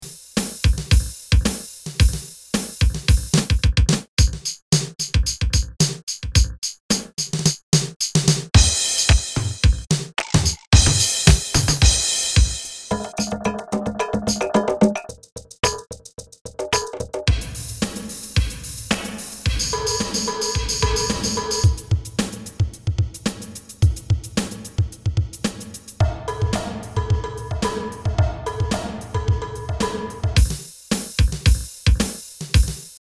Lel MIDIDRUM is a VST/AU plugin that recreates the sound of a rare Soviet drum machine from the late USSR.
The plugin is based on original drum sounds recorded directly from the device.
Many of them have a floating tempo, including #34—a metronome—allowing for lively dynamics in projects. When using these parts, the plugin’s built-in reverb is automatically activated.
Lel MIDIDRUM is a rare, historical sound that likely hasn’t been heard in any setup before.